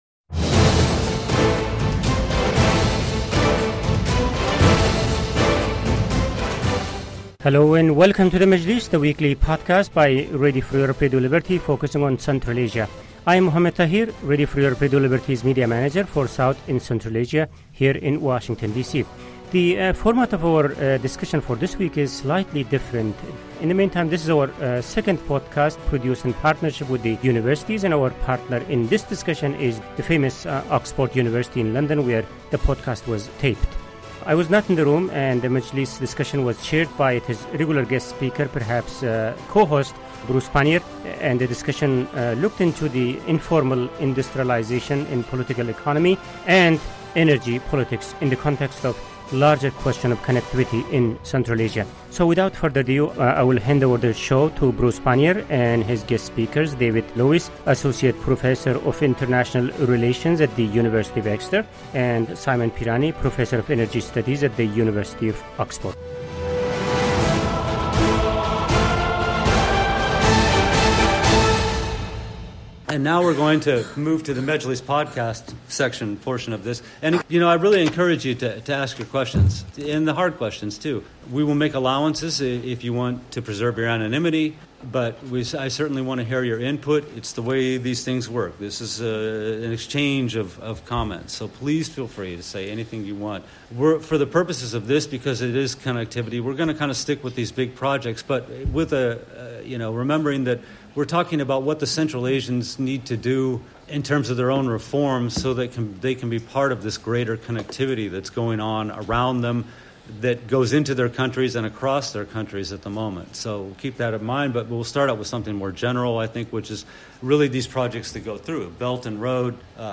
This week’s Majlis comes from the Connecting Central Asia in the 21st Century conference hosted by Oxford University on April 25.